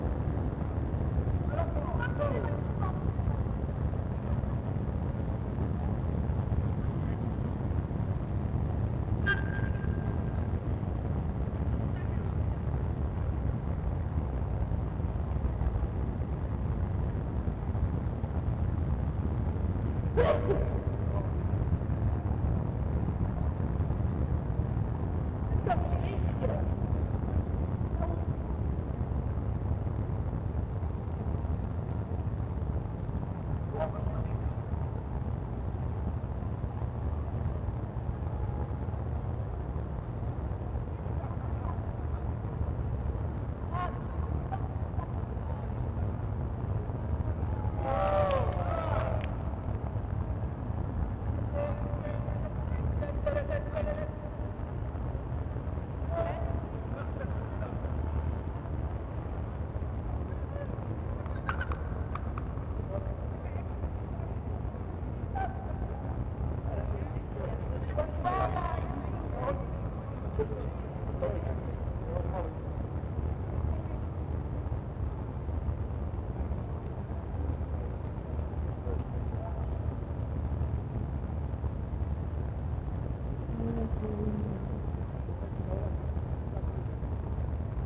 Also I’ve made an ambience plugged-in distortion to make a deafaid-like effect to mimick the blur hearing of a dog.
RH_Demo_Ambience_Distorted.wav